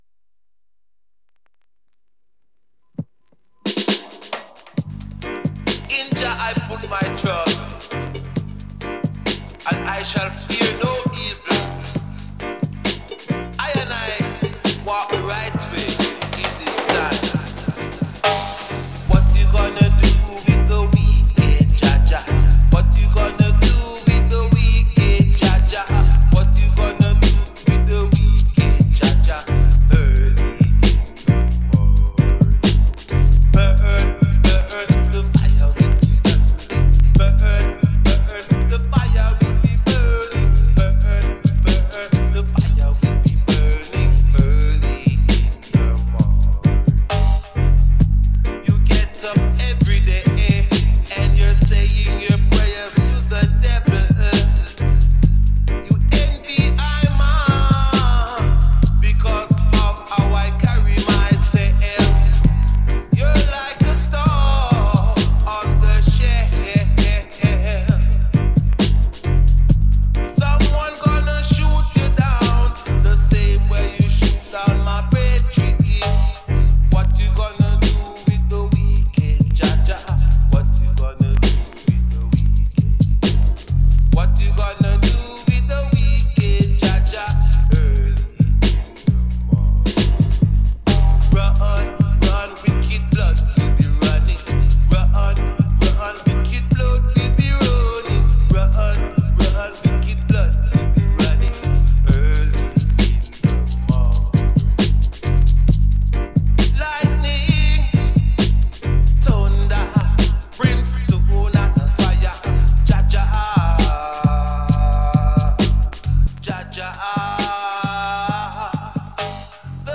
Home recorded. 99,9% Vinyl. One deck.